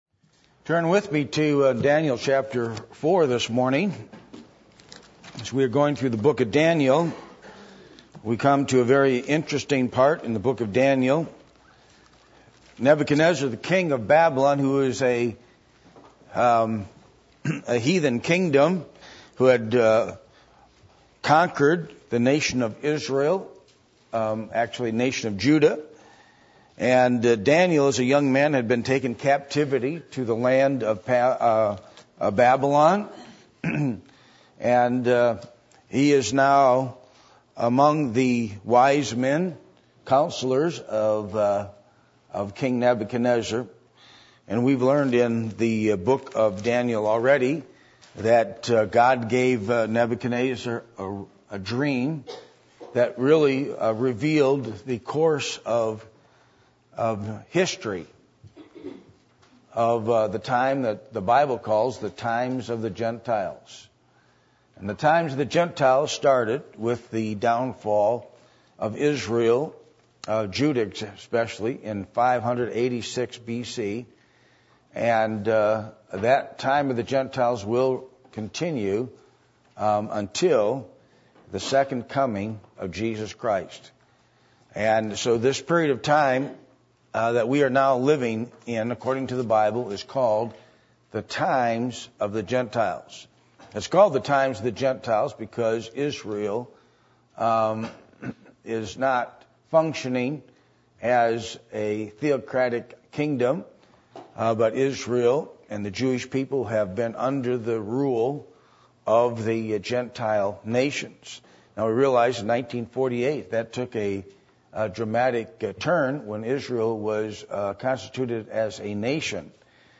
Passage: Daniel 4:1-17 Service Type: Sunday Morning